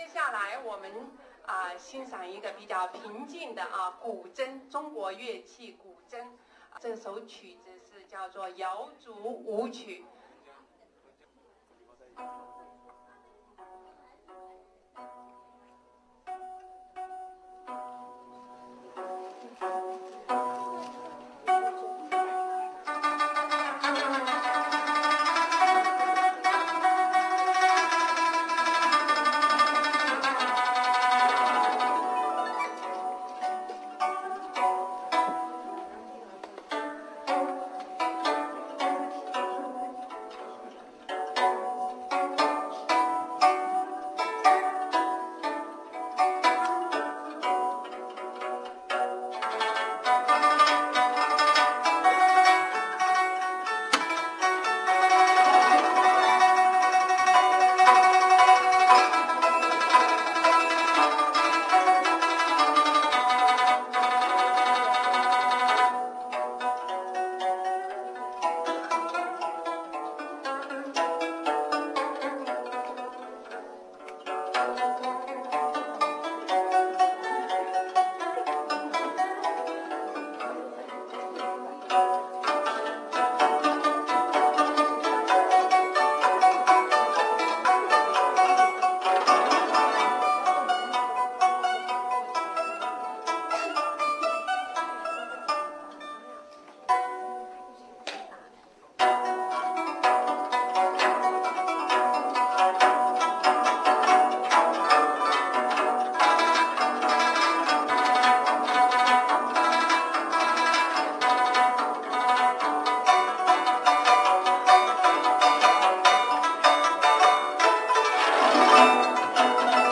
GuZhengQu_YaoZuWuQu_256k.ra